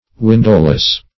Windowless \Win"dow*less\, a.
windowless.mp3